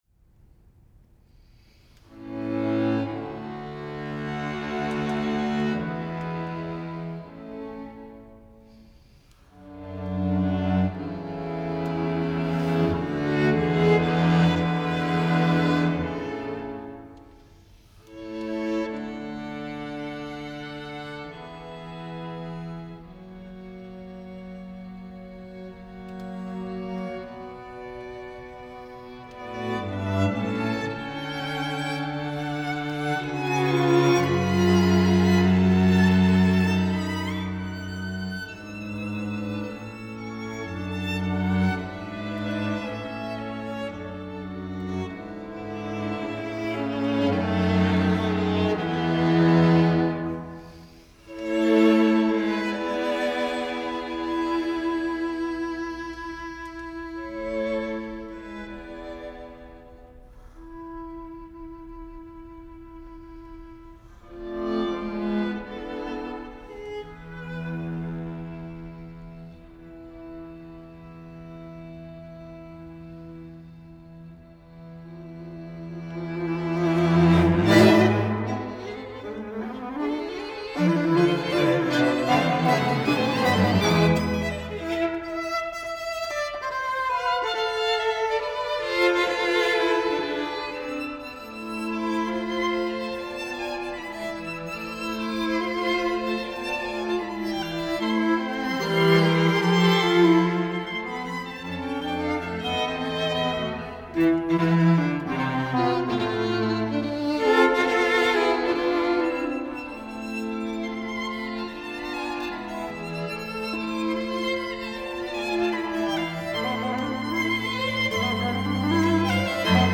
Scroll down to hear live performances of both solo and chamber music masterworks!
String-Quartet-No.-2-in-A-minor-Op.-13-I.mp3